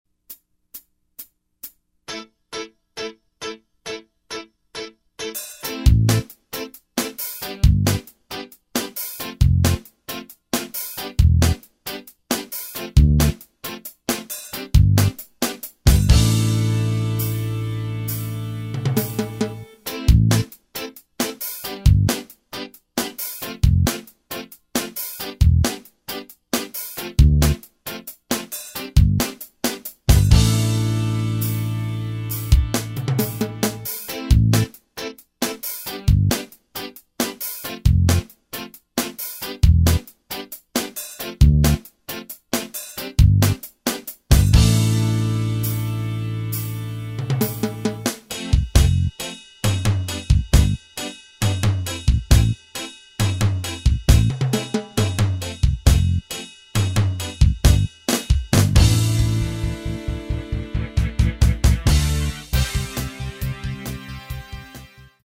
Key of A minor
Backing track only.